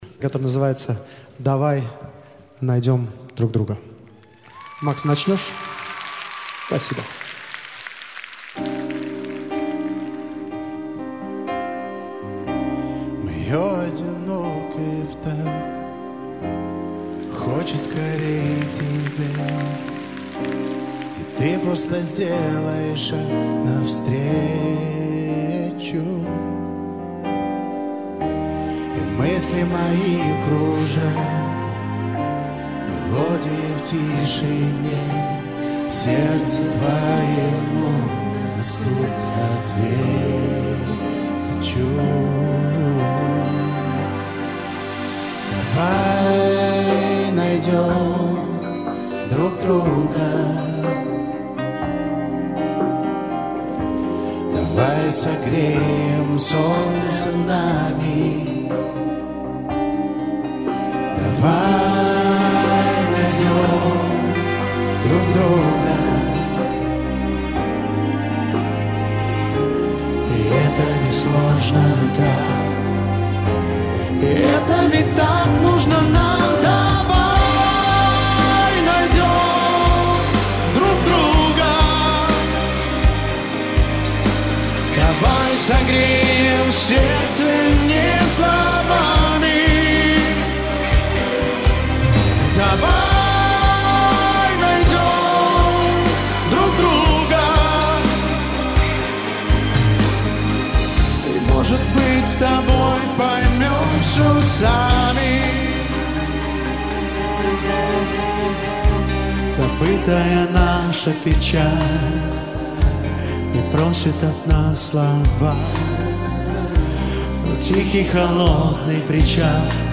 в исполнении дуэта